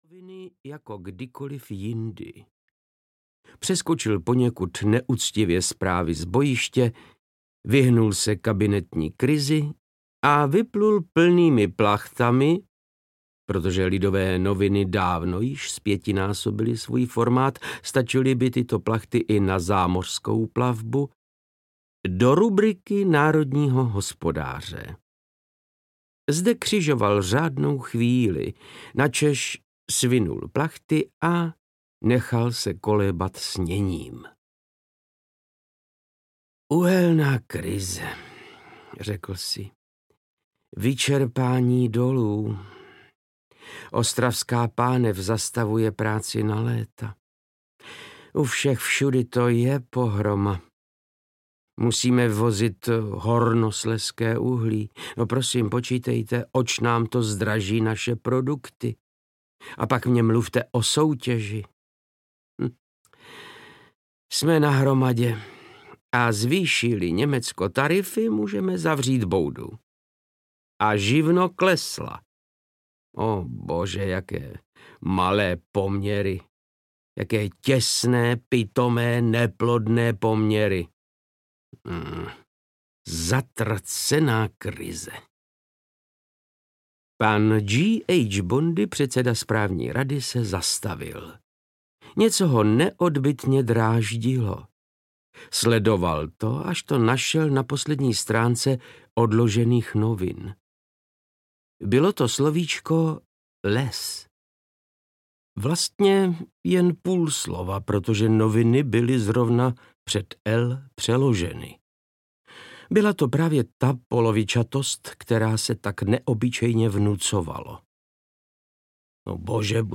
Továrna na absolutno audiokniha
Ukázka z knihy
• InterpretVáclav Knop